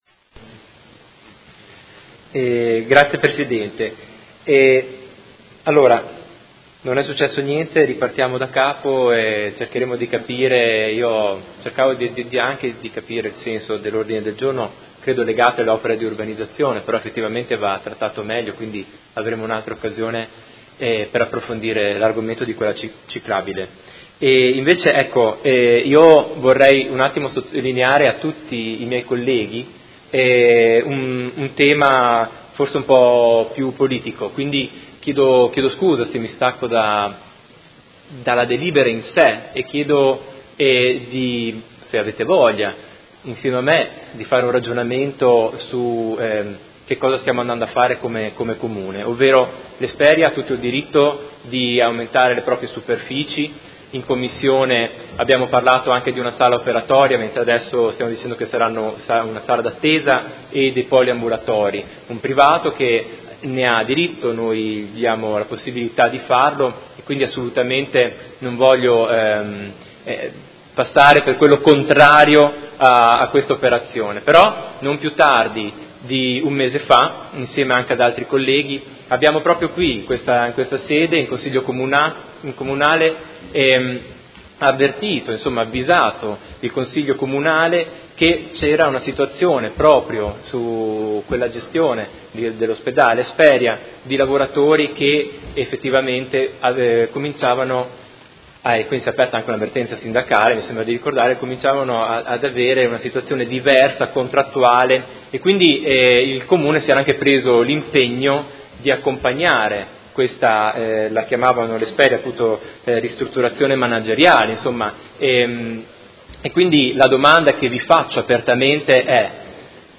Marco Chincarini — Sito Audio Consiglio Comunale
Seduta del 13/07/2017 Dibattito.